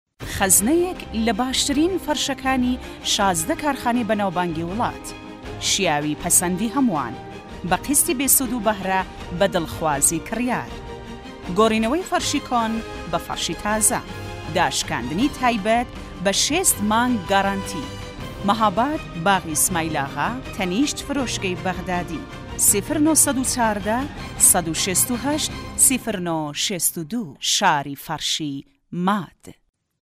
Female
Adult
Documentary
Elearning